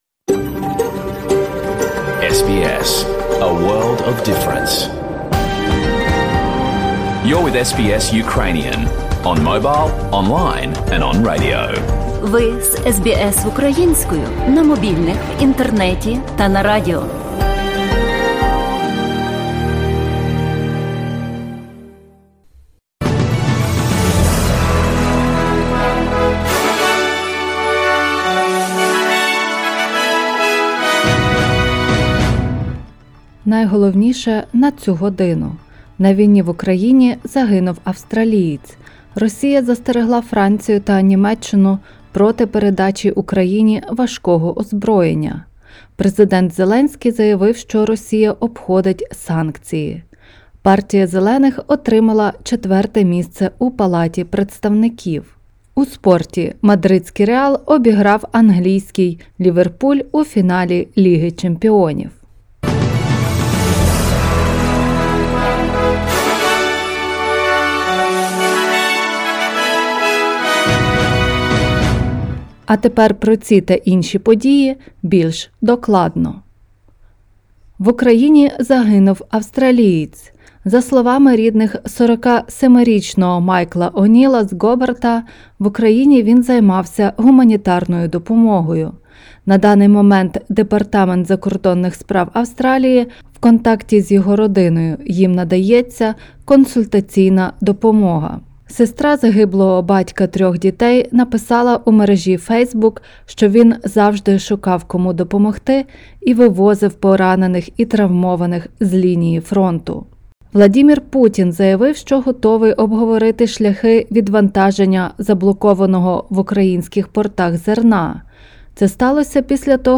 SBS news in Ukrainian - 29/05/2022